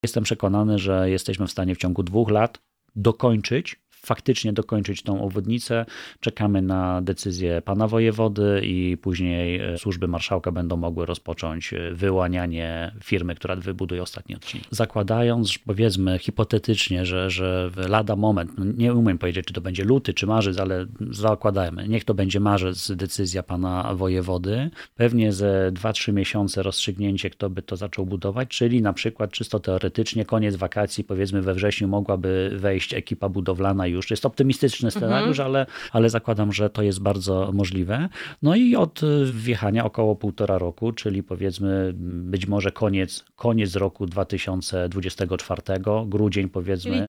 O inwestycji rozmawiamy z Michałem Bobowcem – radnym Sejmiku Województwa Dolnośląskiego.